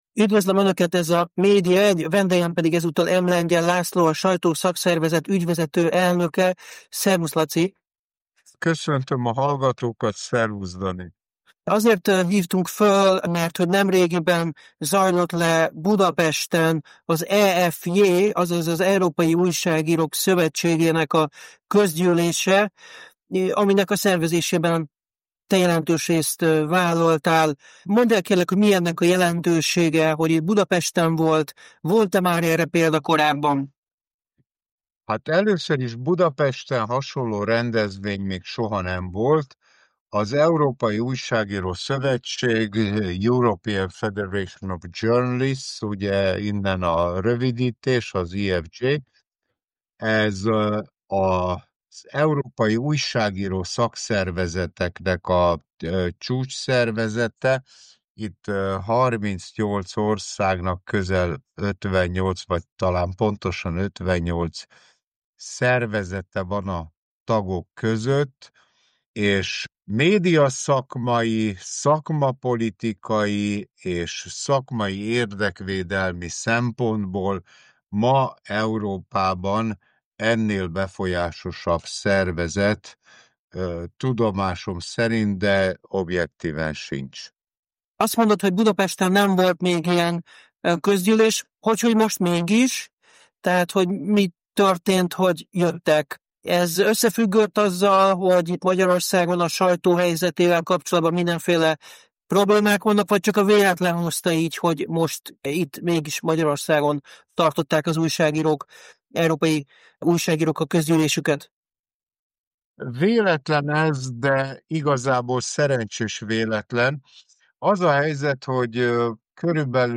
Tarthatatlan a magyar médiahelyzet – Budapesten ülésezett az EFJ – interjú